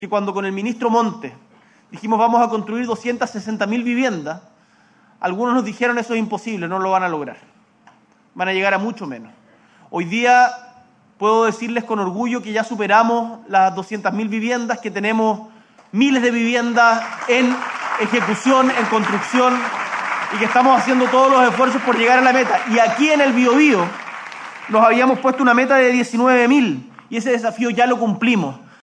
En la ceremonia, el Presidente también ahondó en los avances del Plan de Emergencia Habitacional.